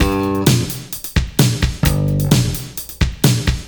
• 131 Bpm Breakbeat Sample G Key.wav
Free drum loop - kick tuned to the G note. Loudest frequency: 2214Hz
131-bpm-breakbeat-sample-g-key-ukm.wav